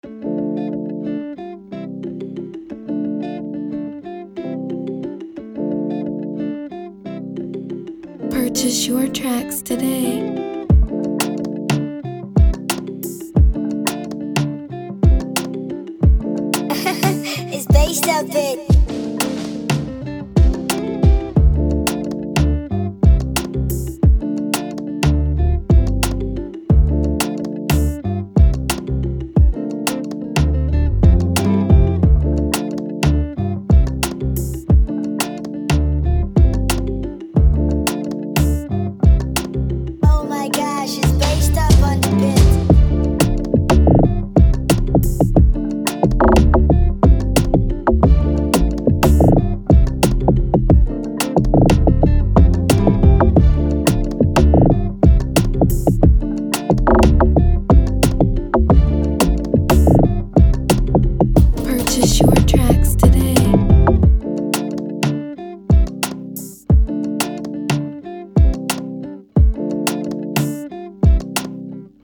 With its vibrant melodies and irresistible beats